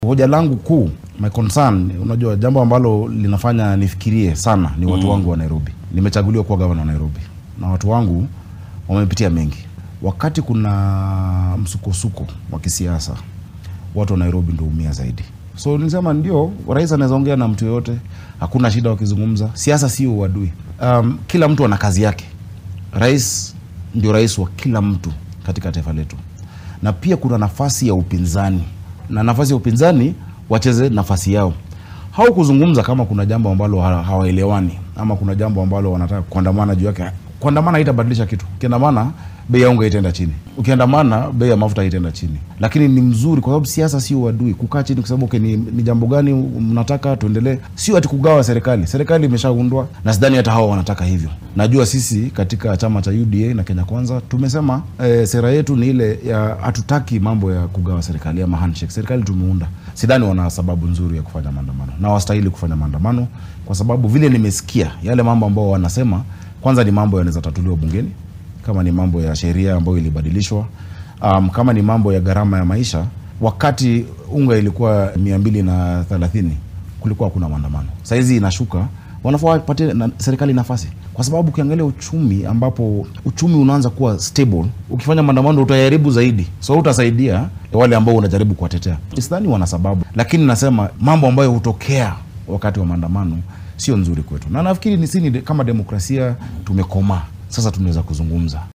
Guddoomiyaha ismaamulka Nairobi Johnson Sakaja oo wareysi gaar ah siiyay telefishinka NTV ayaa sheegay in aynan jirin sabab wax ku ool ah oo ay hadda siyaasiyiinta isbeheysiga mucaaradka ee Azimio ay gudaha wadanka uga dhigaan dibadbaxyo. Waxaa uu ku baaqay in madaxda ugu sarreysa wadanka ay wada hadlaan.